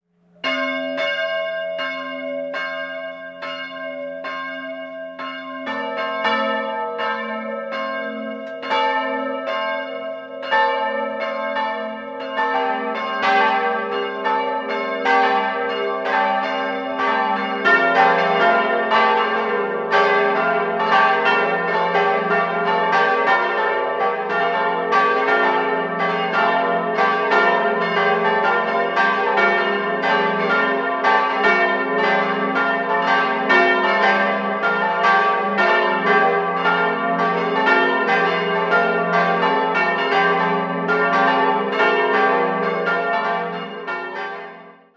4-stimmiges ausgefülltes F-Dur-Geläute: f'-g'-a'-c'' Alle vier Glocken stammen von Karl Hamm, Regensburg, und wurden zwischen 1946 und 1950 gegossen (exaktes Gussjahr ist derzeit nicht bekannt). Das Gesamtgewicht beträgt etwa 1750 kg.